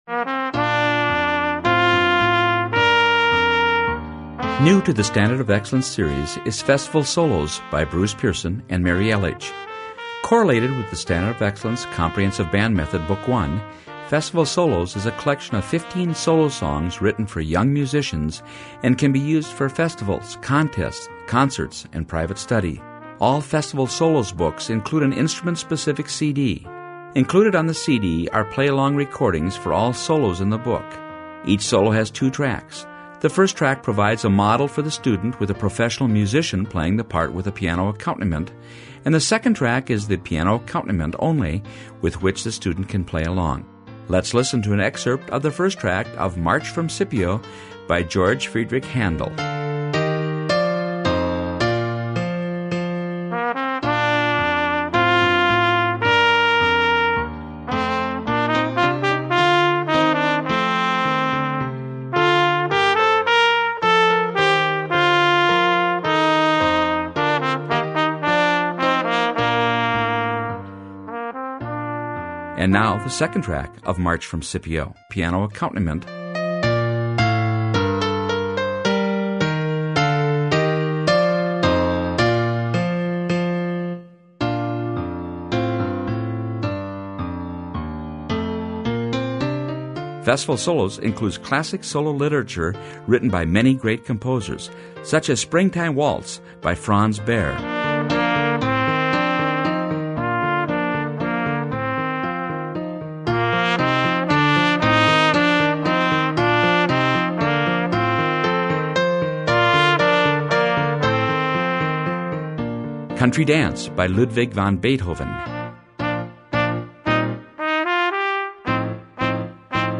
solo literature